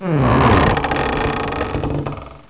creak2.wav